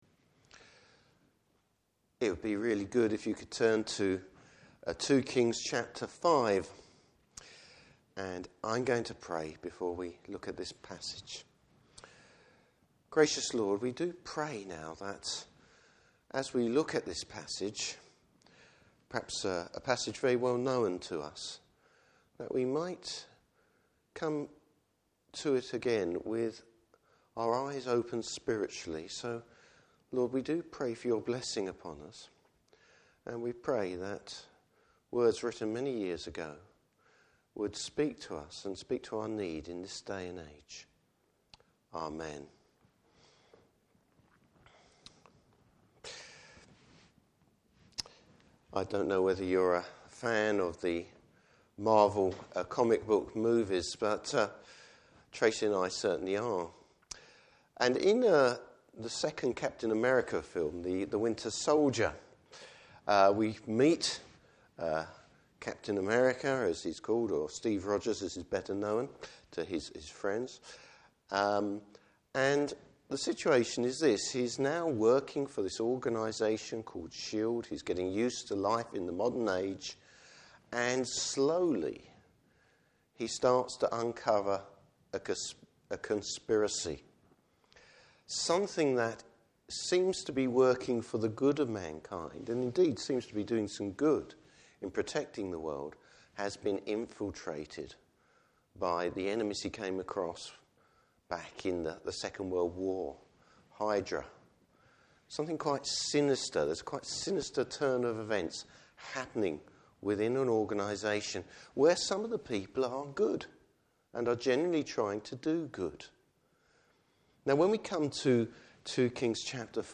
Service Type: Evening Service Bible Text: 2 Kings 5:1-27.